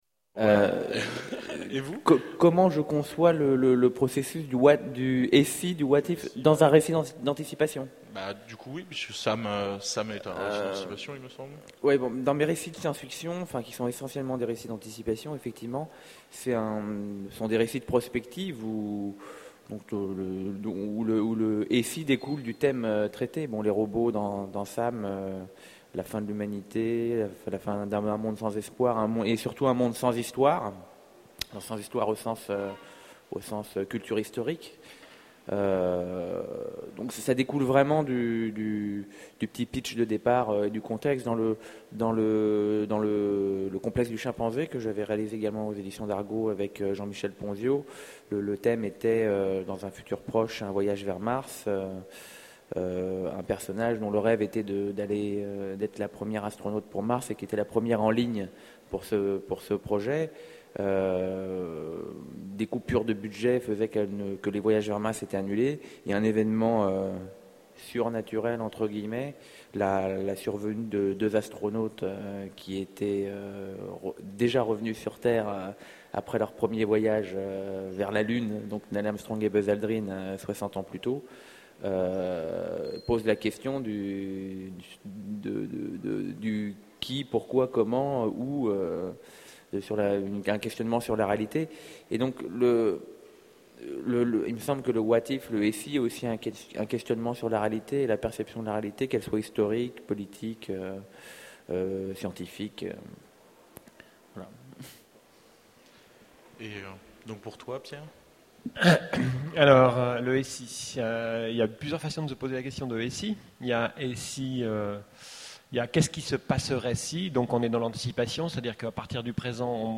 Utopiales 2011 : Conférence Les anticipations sont-elles des uchronies ?